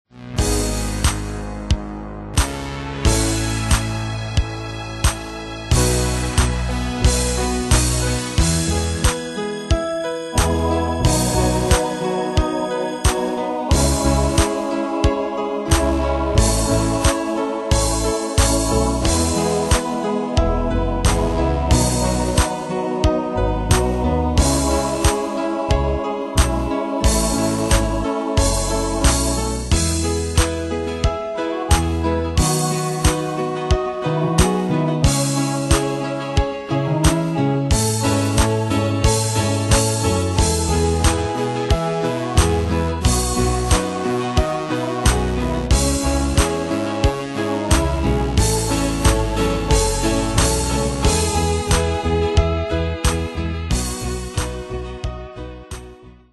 Style: PopAnglo Ane/Year: 2007 Tempo: 90 Durée/Time: 4.22
Danse/Dance: Ballade Cat Id.
Pro Backing Tracks